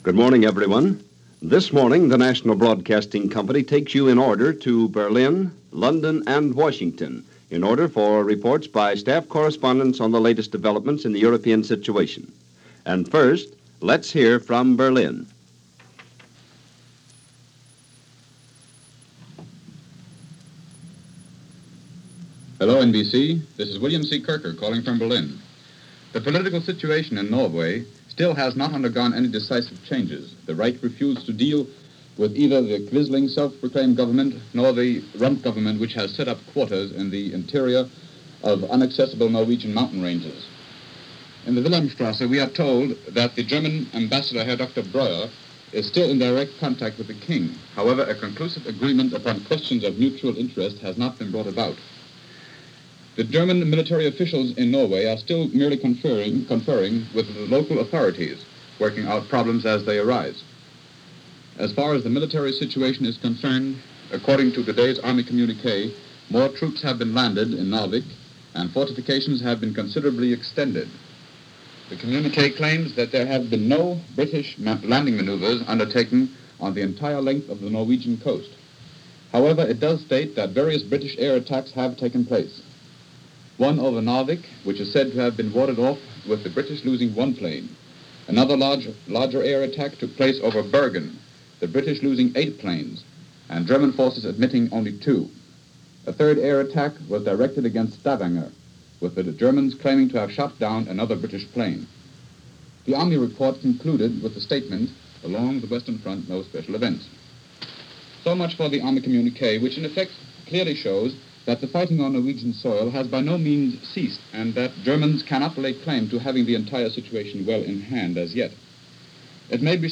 News for this day in 1940.